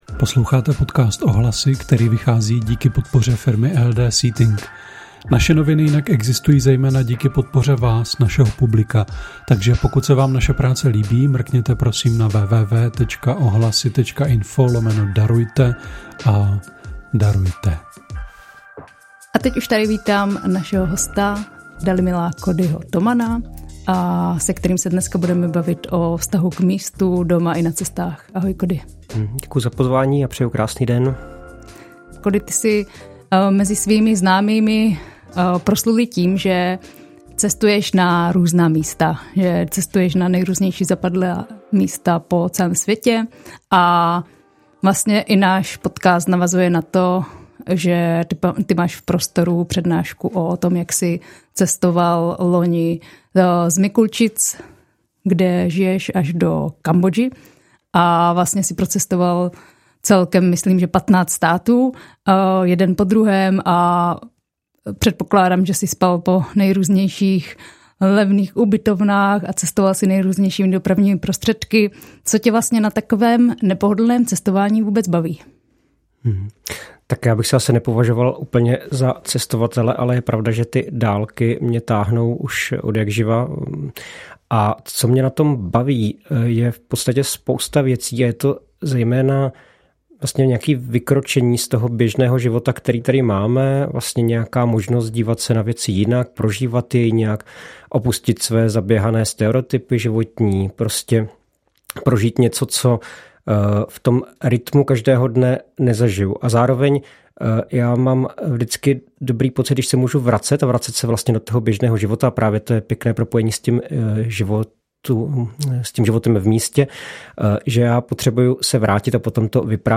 O tom všem v rozhovoru mluvíme.